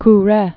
(krĕ)